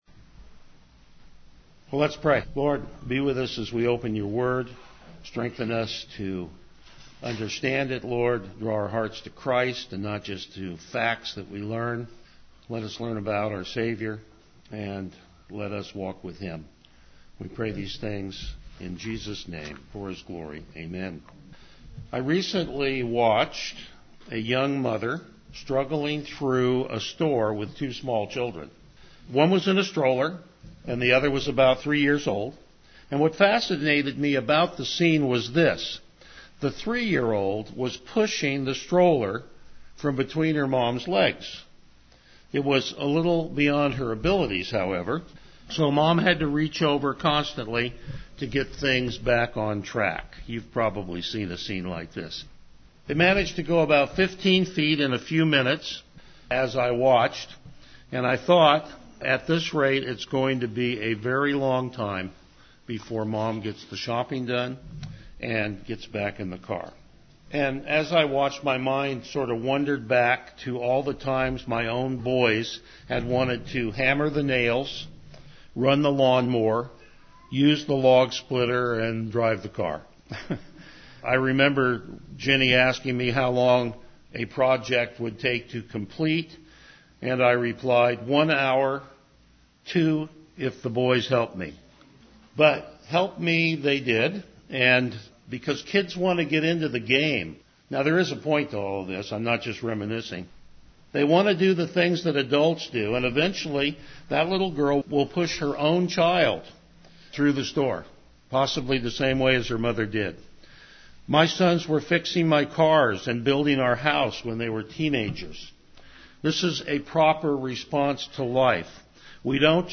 Passage: Hebrews 9:1-14 Service Type: Morning Worship
Verse By Verse Exposition